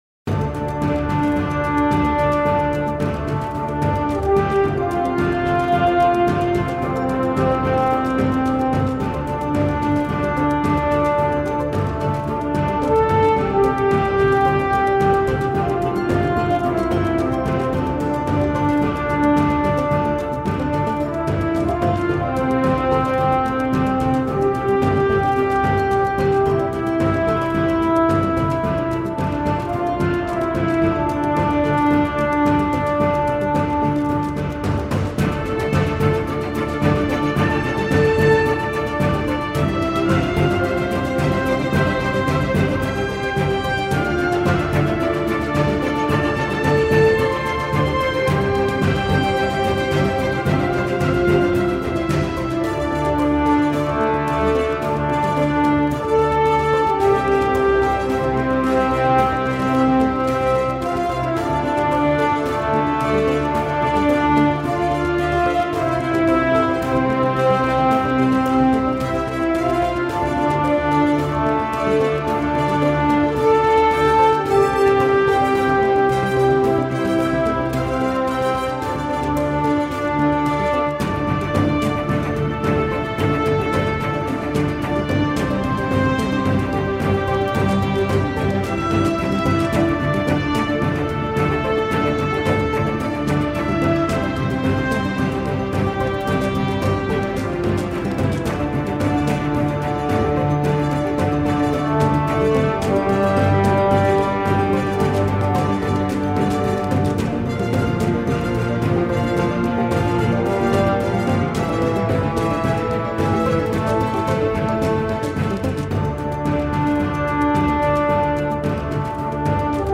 Orchestral track for RPG and epic universe.